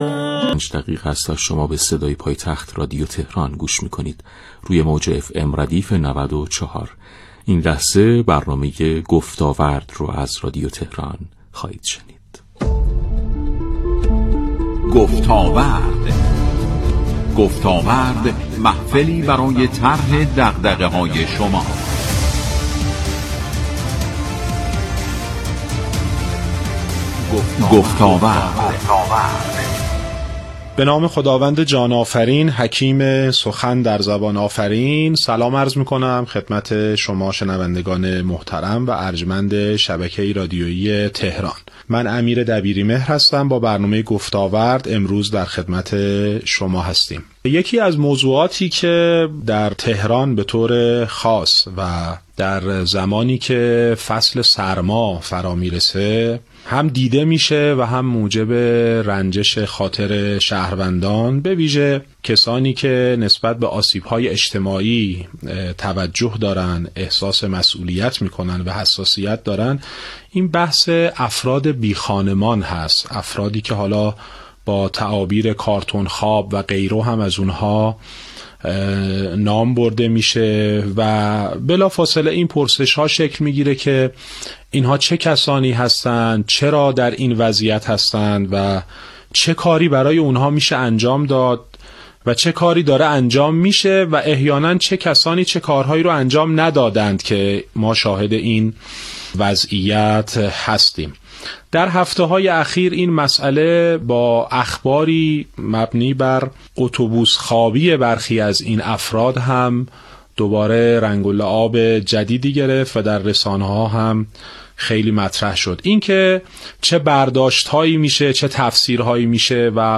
اسلایدر / مهمترین مطالببرنامه رادیوییمصاحبه و گفتگو